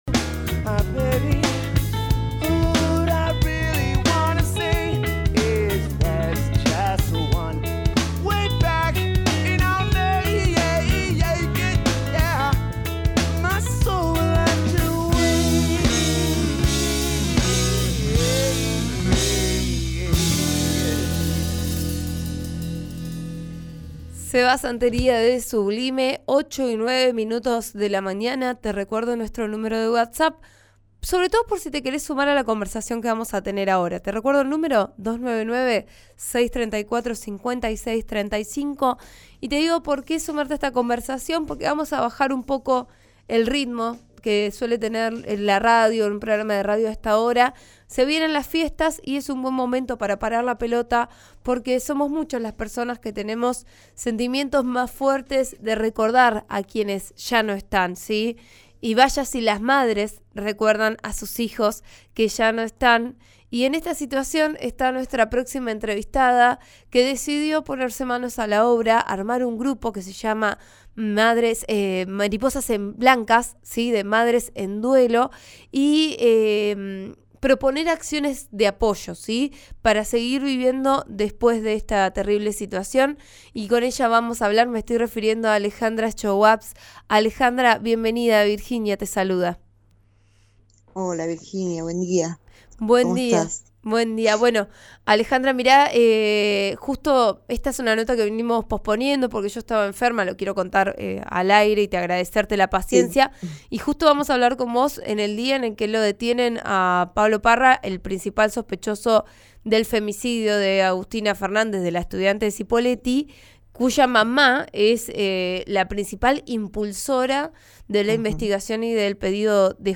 describió el accidente en una charla con Vos A Diario, por RN Radio